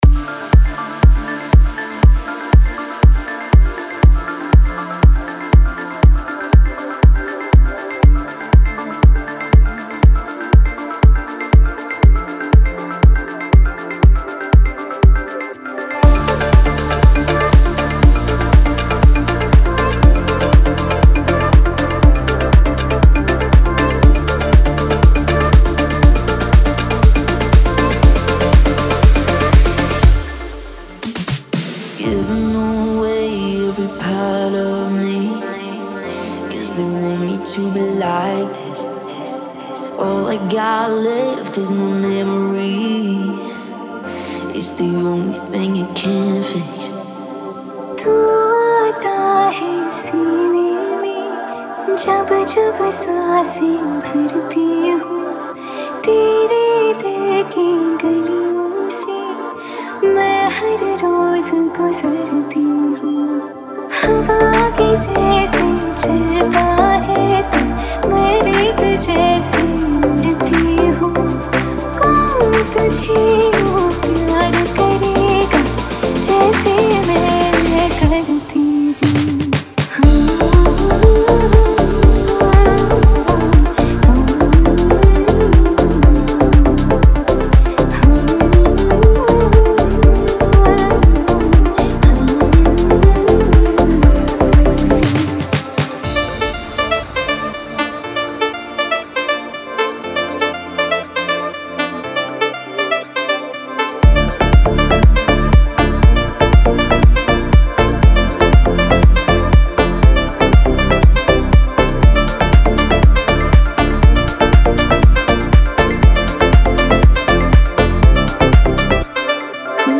Bollywood Deep House
Bollywood DJ Remix Songs